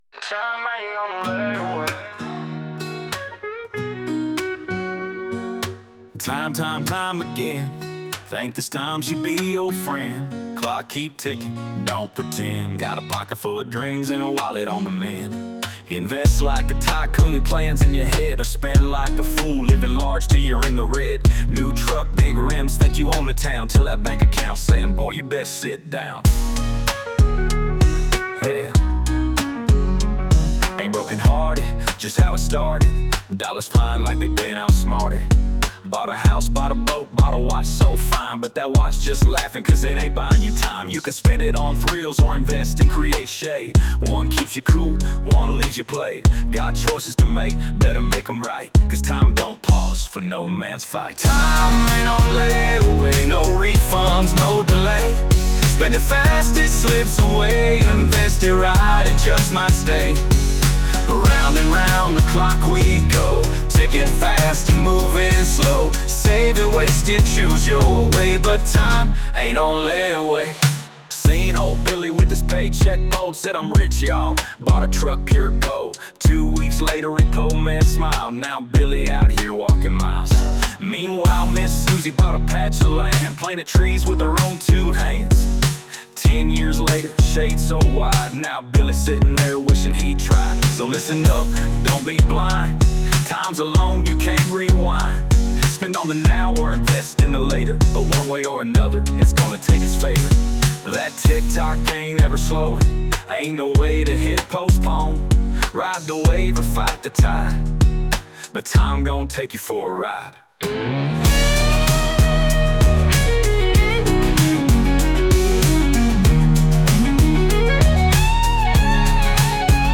Country / Folk